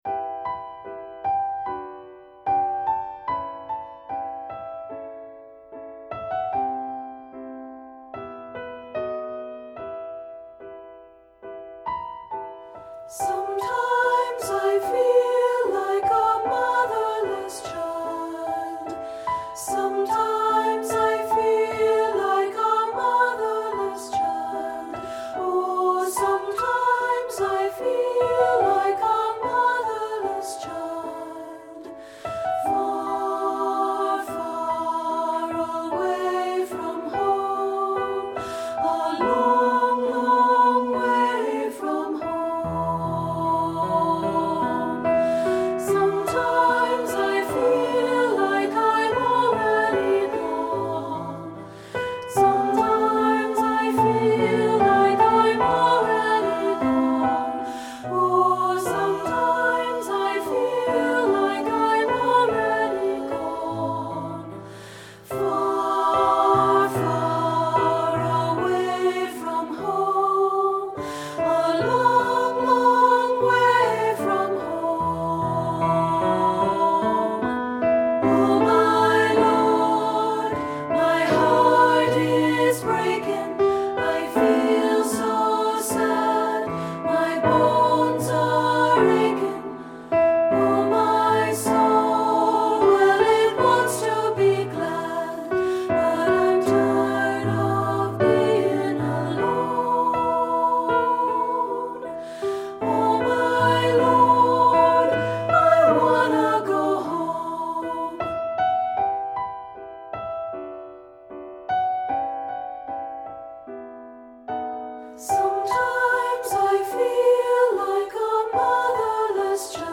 Composer: Spirituals
Voicing: Unison|2-Part